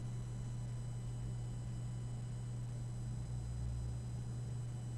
Although different surface recording reduces the hum from the fans quite a bit, nothing corrects the hiss. It’s like some kind of interference.
Currently on a desktop set-up w/ a Realtek onboard sound card, Blue Yeti microphone and Turtle Beach X12 headphones.
That’s the high-itched mosquito whine in the background after you get rid of the 120 Hz power hum.
Yes, that high pitch whistle sounds and looks very much like electrical interference - probably picked up from the computer via the USB connection.